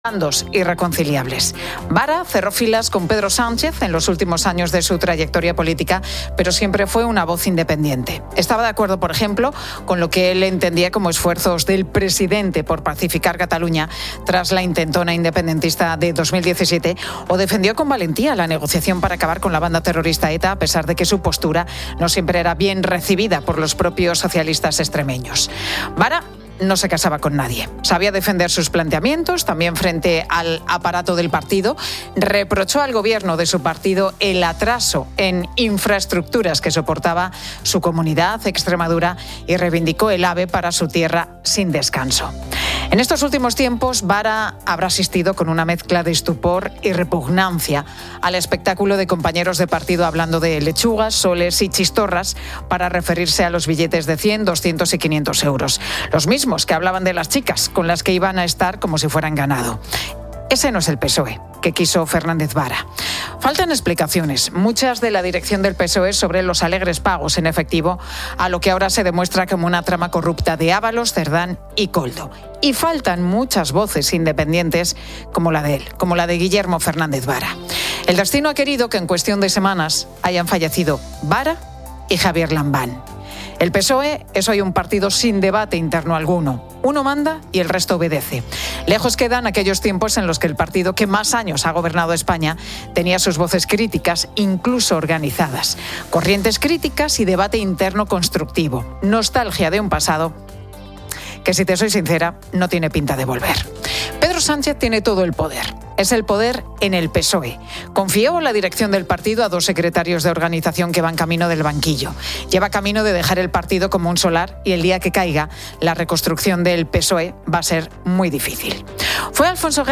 Hablamos con su autor